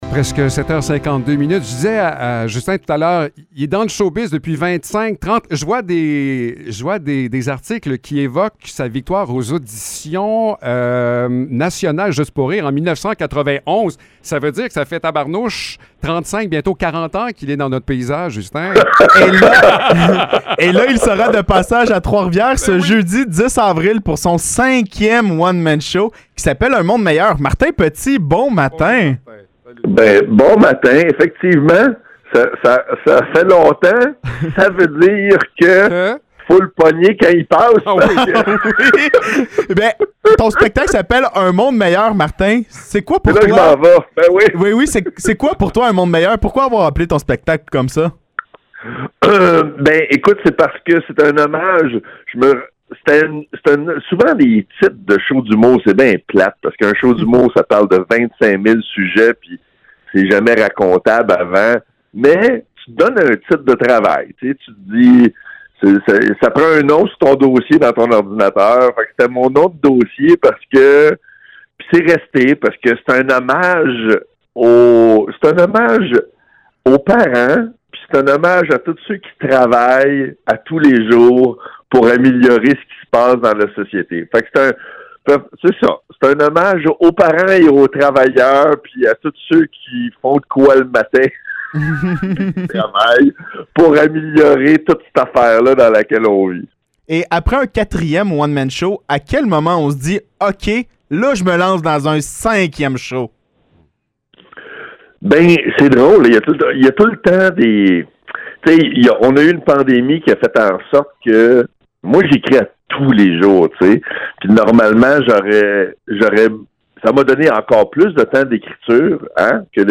Entrevue avec Martin Petit